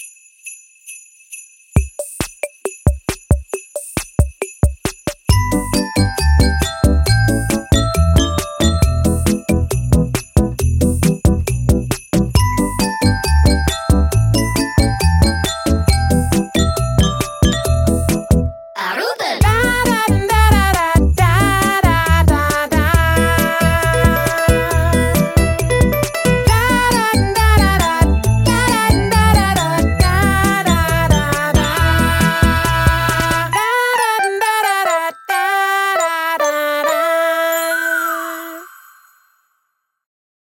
Download ARUBEN-jinglen som jule-ringetone